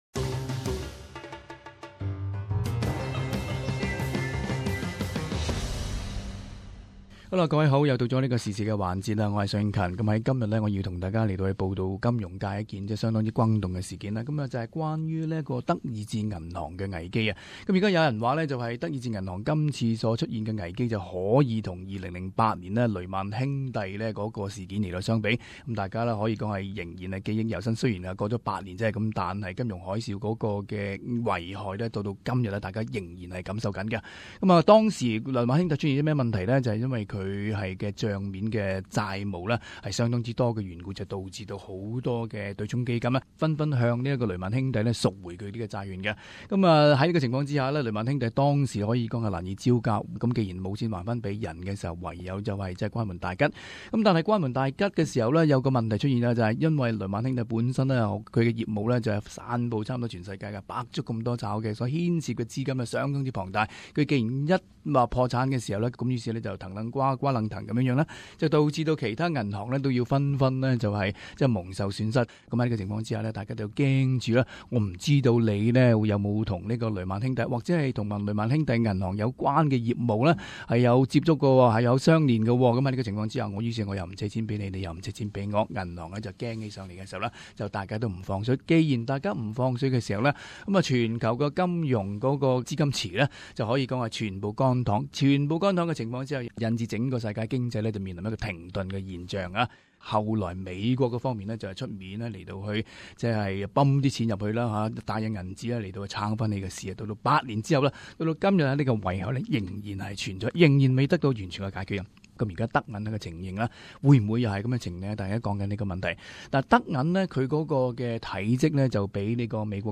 【時事報導】德意志銀行危機|雷曼兄弟翻版？